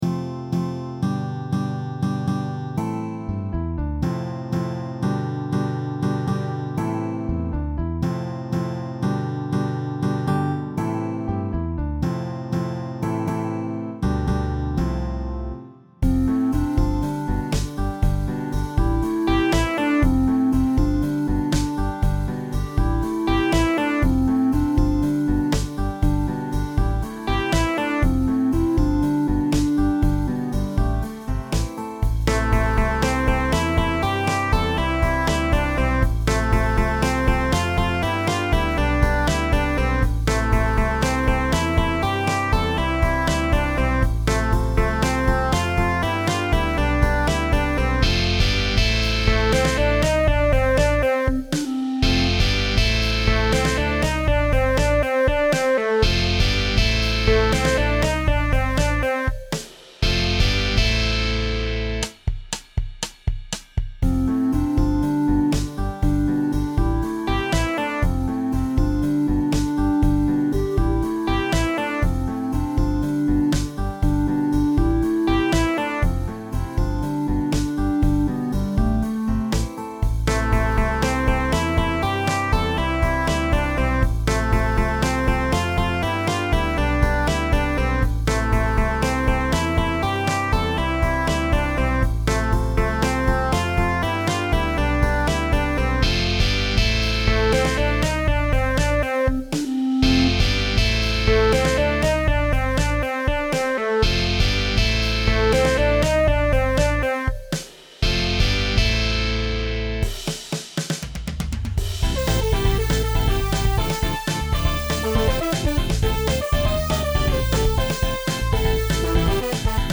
PopMusic
The vocal part uses multiple synthesizers.